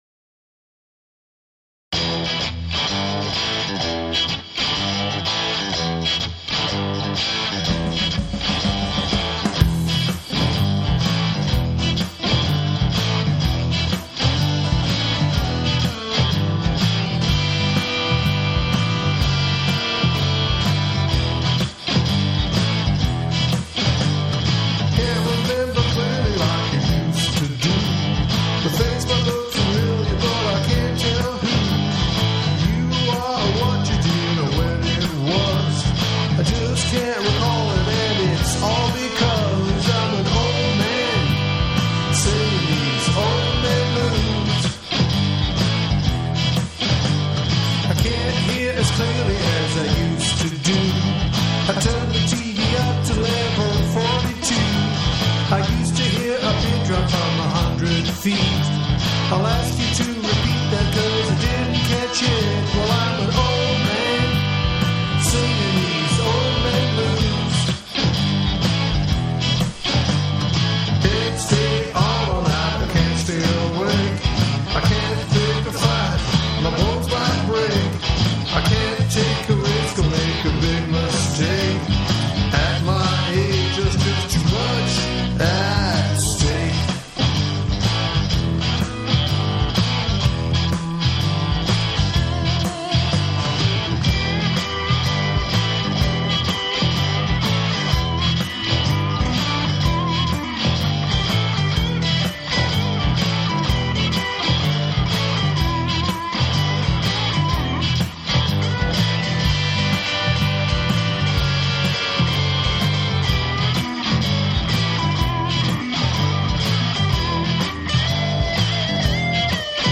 It did remind me though of one of my earliest recordings after discovering the joys of Garageband. I think this one dates back to 2008 + or -, so the production is a bit more shrill than I would like, and I’ve tried to quickly remaster it to smooth it out.
old-man-blues-remaster-1-2.m4a